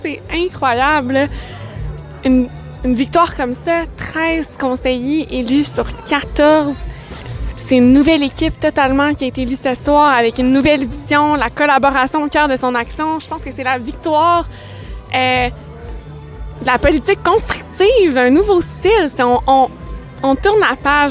Les cris de joie remplissaient la salle du Théâtre de la Providence du Collège Notre-Dame-de-Lourdes.